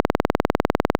scroll_002.ogg